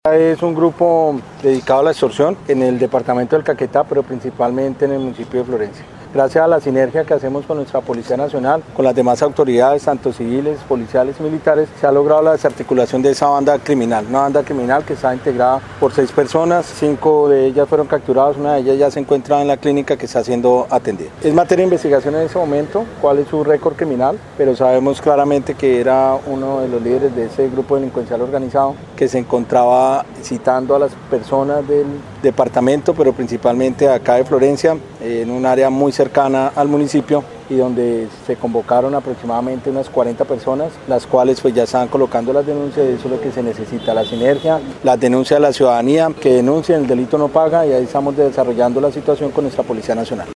GENERAL_LUIS_SALGADO_ROMERO_OPERATIVO_-_copia.MP3